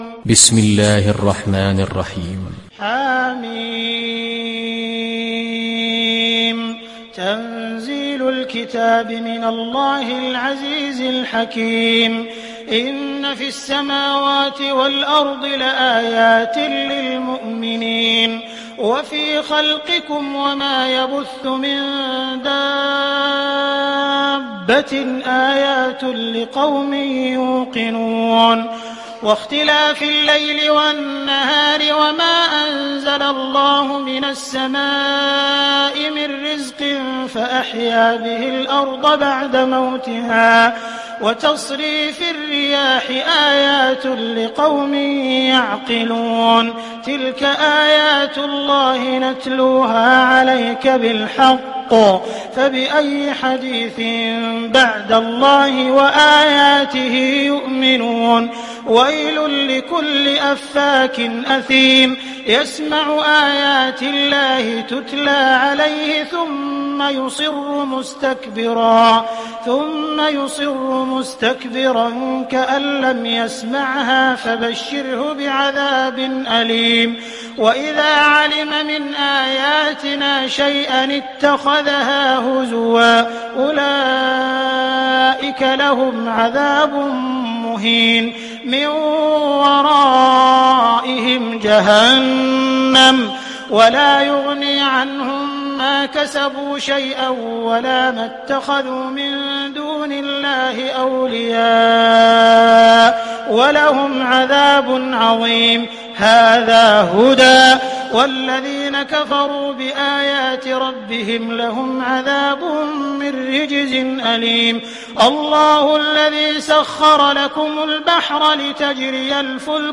Sourate Al Jathiyah Télécharger mp3 Abdul Rahman Al Sudais Riwayat Hafs an Assim, Téléchargez le Coran et écoutez les liens directs complets mp3